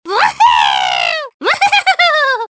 One of Toad's voice clips in Mario Kart 7